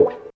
137 GTR 2 -R.wav